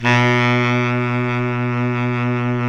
Index of /90_sSampleCDs/Roland LCDP07 Super Sax/SAX_Baritone Sax/SAX_40s Baritone
SAX B.SAX 04.wav